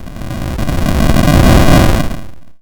Explode_05.mp3